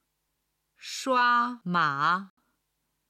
今日の振り返り！中国語発音